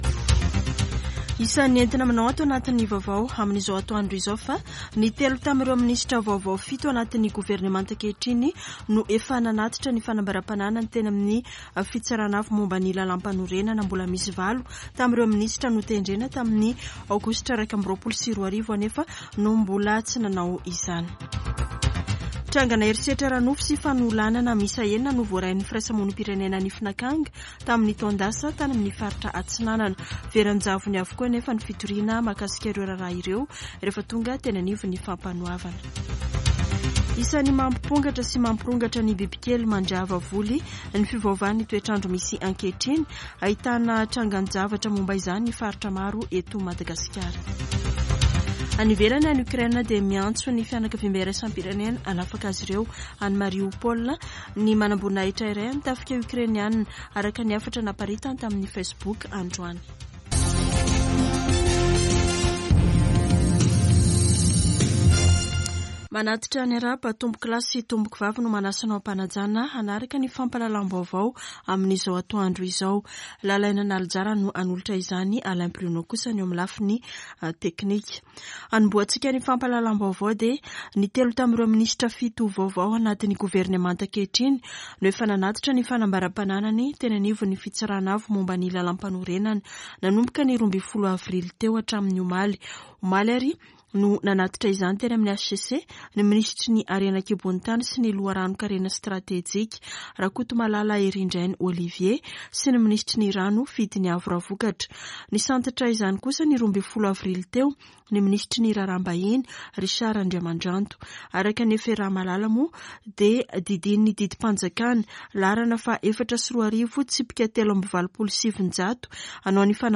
[Vaovao antoandro] Alarobia 20 aprily 2022